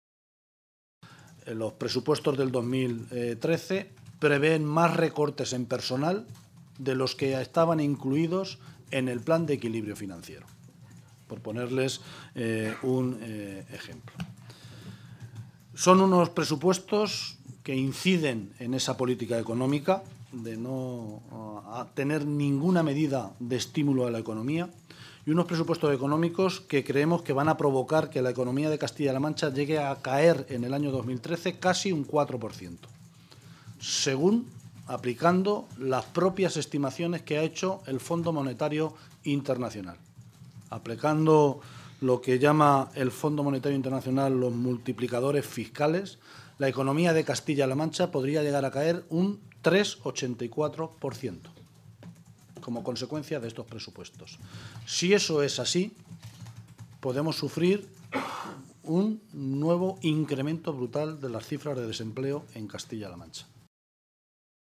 Guijarro realizó estas declaraciones en una rueda de prensa conjunta con los responsables sindicales de CC OO y UGT